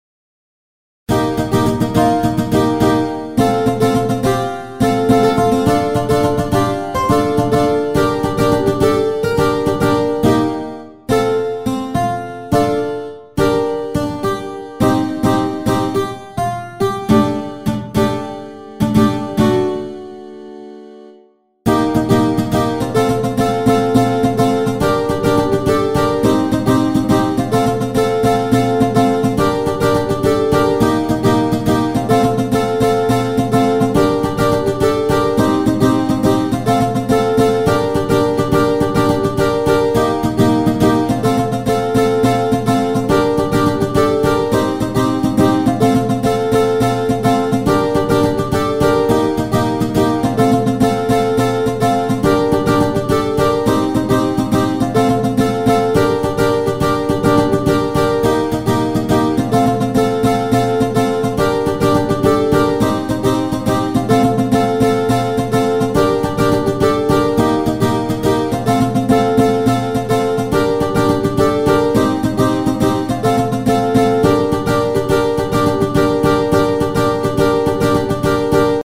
HALion6 : A.Guitar
Folk Steel String